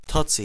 Summary Description Totse.ogg English: Audio pronunciation of ' TOTSE '.